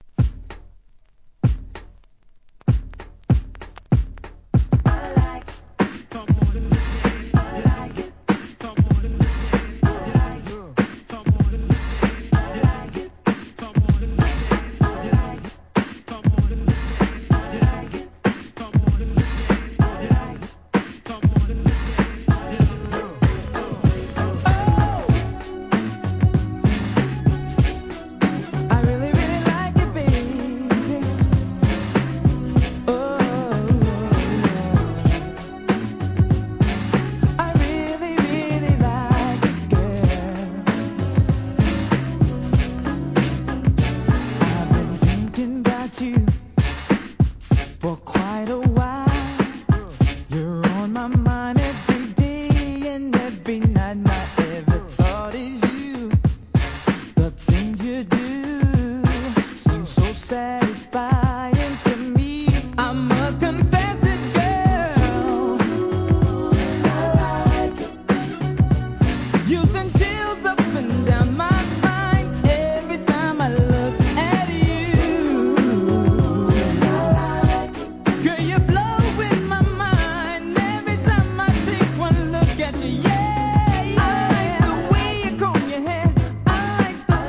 HIP HOP/R&B
定番ブレイク